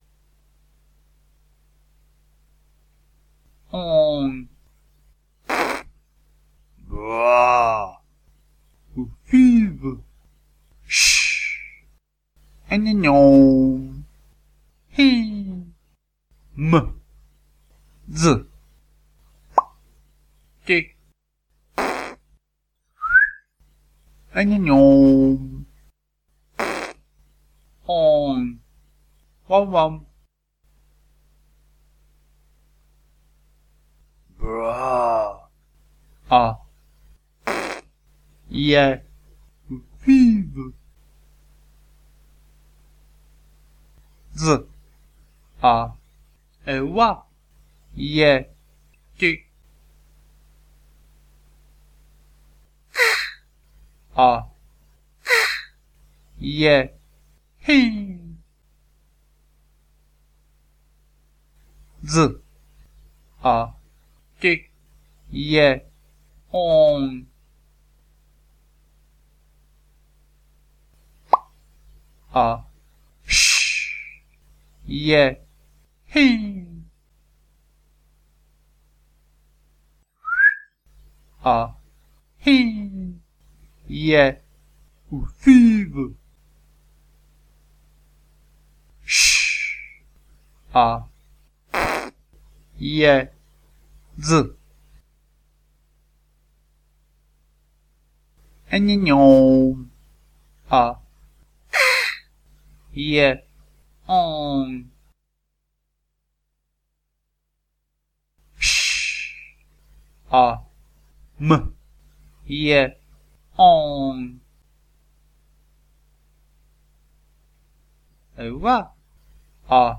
Šifra se skládá ze dvou různých bloků zvuků. Jednak souvislého bloku patnácti zvuků, dále většího počtu pětic zvuků.
óň prd bruá ufív šš eněňou íng mh dz plop ť prd písk eněňou prd óň uamuam
V pěticích je vždy druhý zvuk ,a‘ a čtvrtý ‚je‘, což dá následující relace: